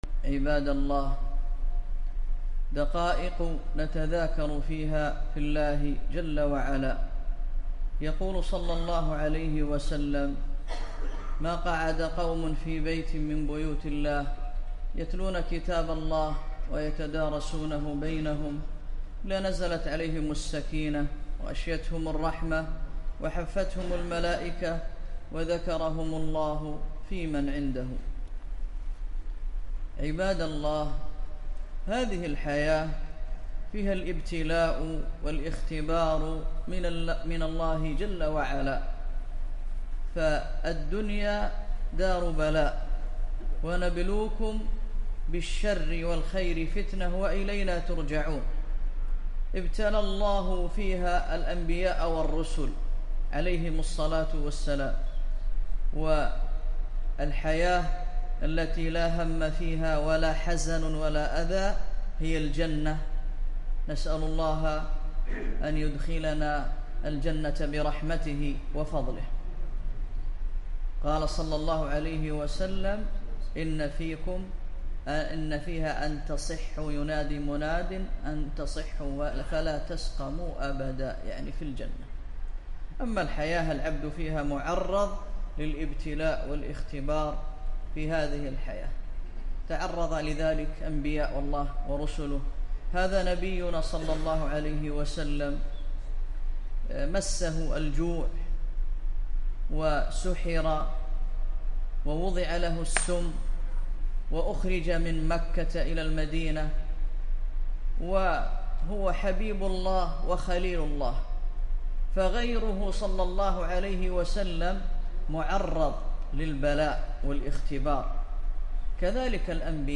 محاضرة - مخالفات في الرقية الشرعية